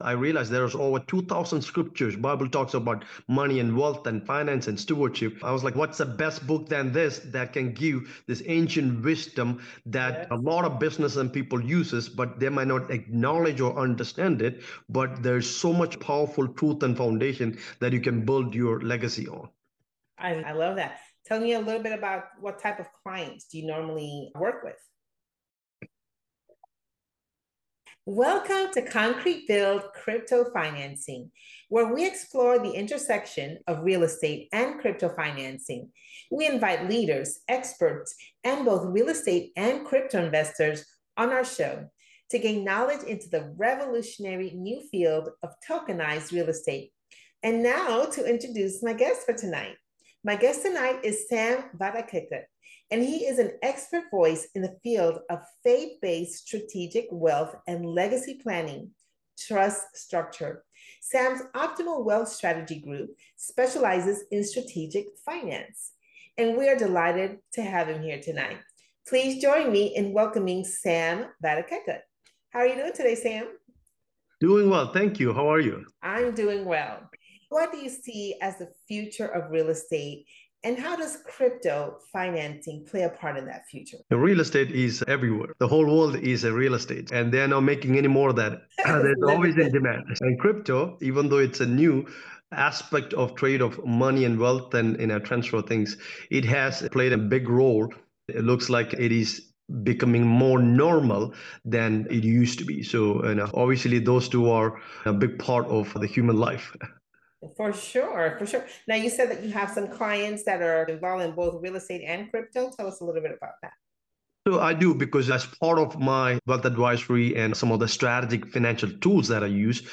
an enlightening conversation on the intersection of real estate, crypto, and faith-based financial planning